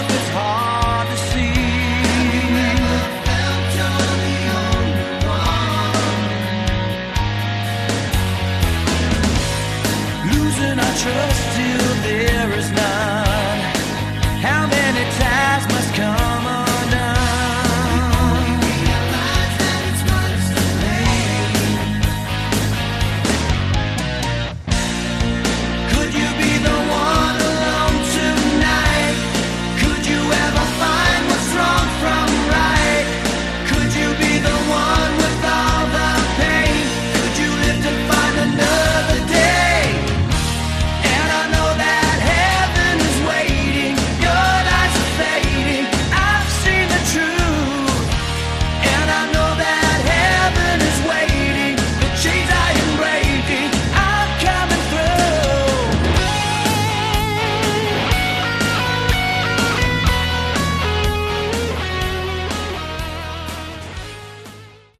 Category: Melodic Metal
guitar, keyboards and vocals
drums and vocals